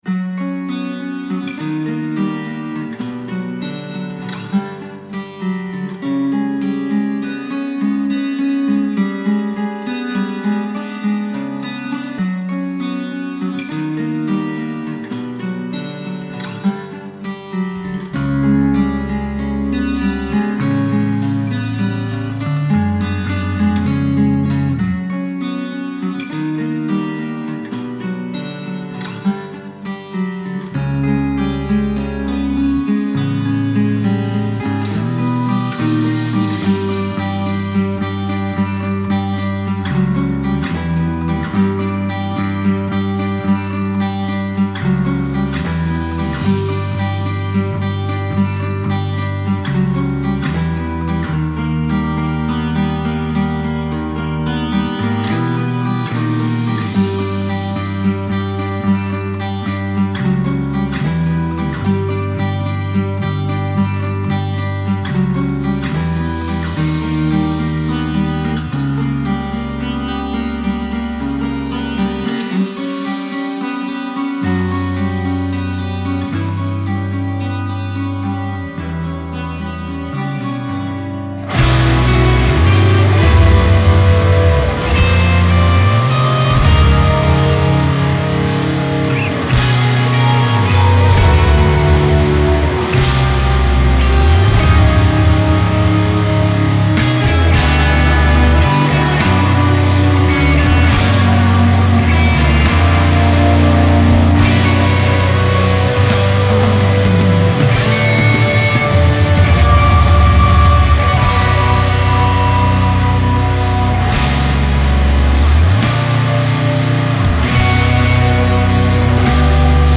ROCK/METAL